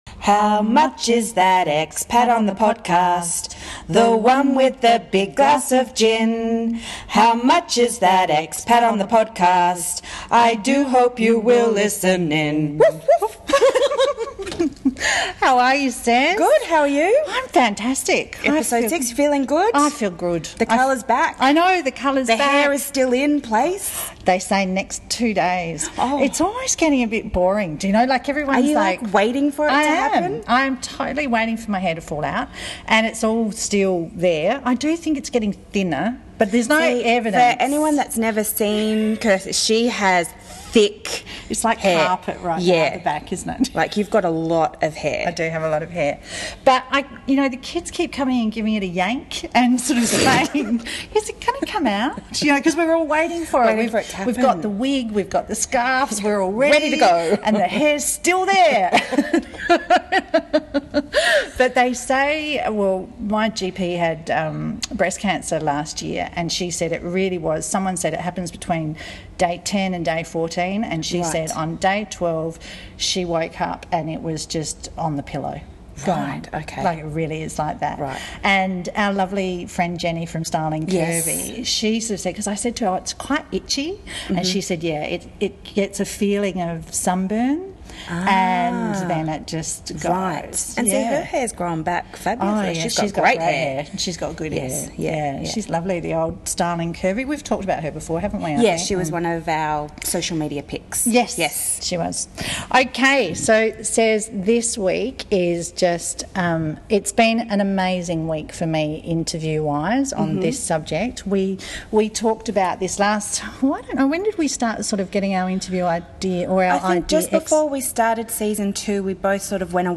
Today we talk to three amazing women about their journey through the adoption process as expats.